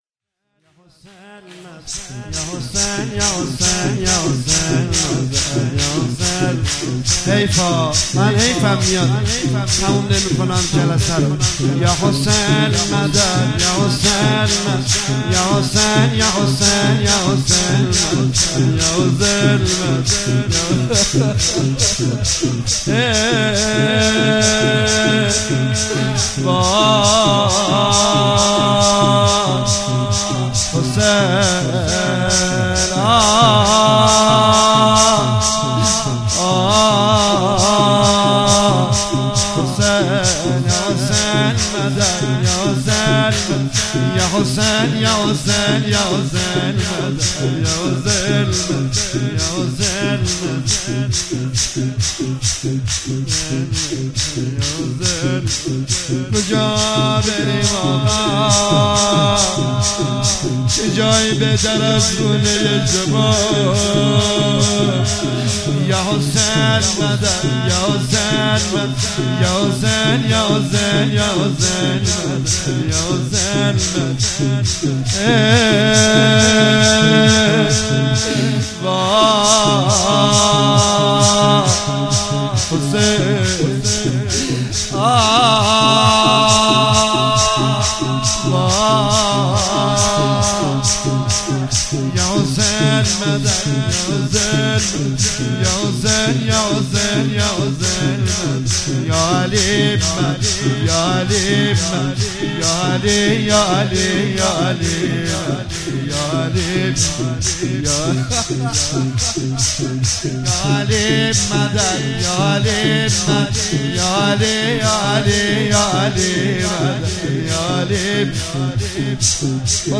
16 تیر 96 - هیئت رزمندگان - شور - دنبال حیدر میدوید